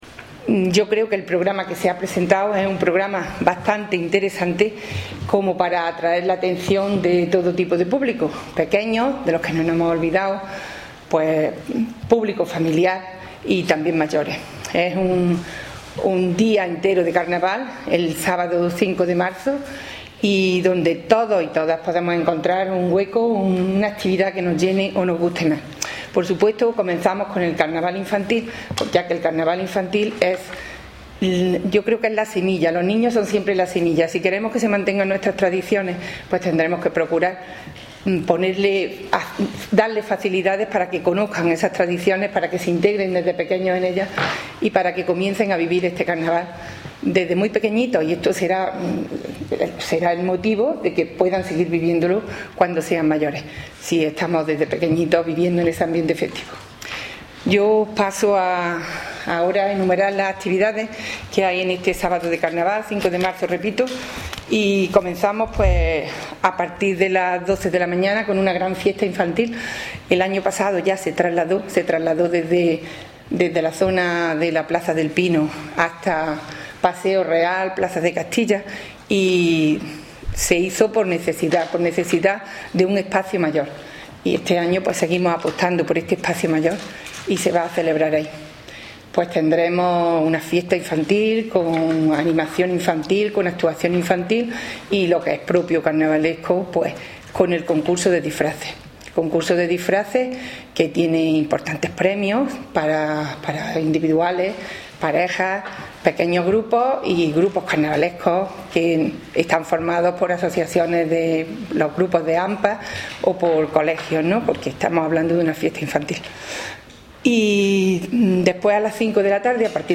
Cortes de voz
Audio: concejala de Cultura   3014.69 kb  Formato:  mp3